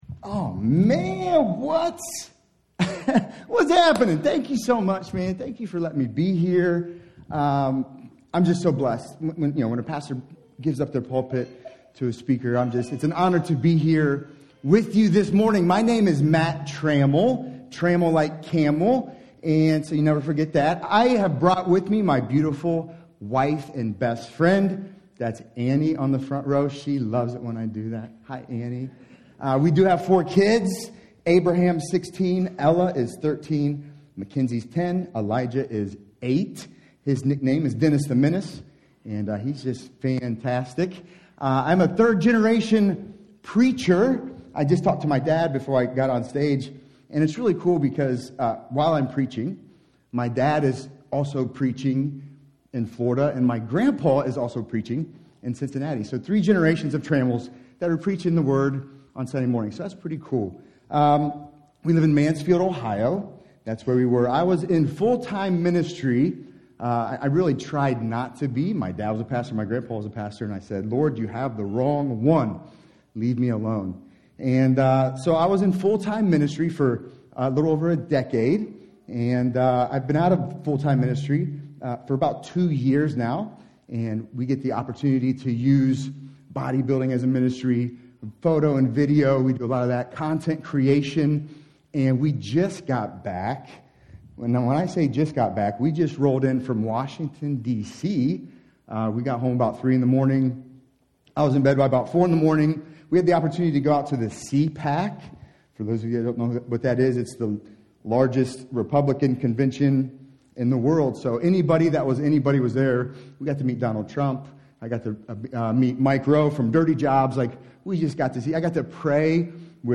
Sermons | Mennonite Christian Assembly